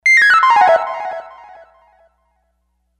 Falling
Falling sound